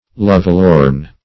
Lovelorn \Love"lorn`\, a.